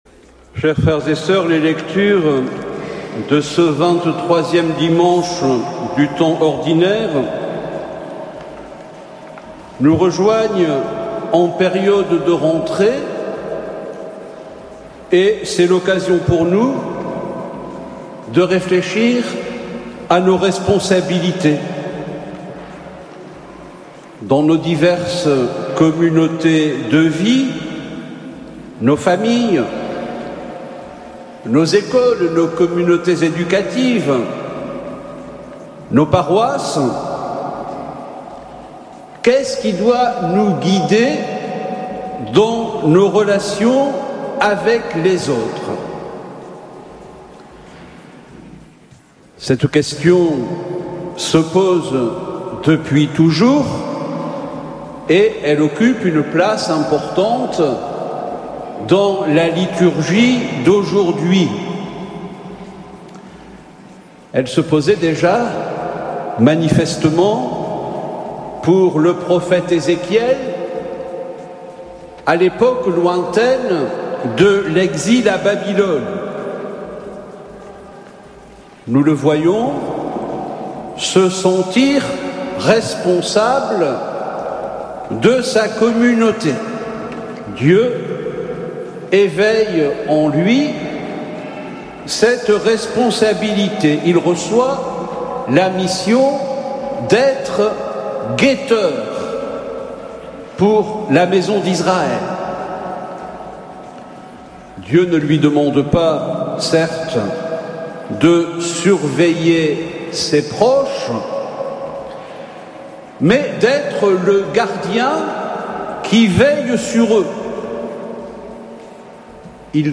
Plusieurs centaines de personnes se sont rendues présentes ce dimanche à Sainte Anne d’Auray.
Homelie-Pardon-Enseignement-Catholique-RCF.mp3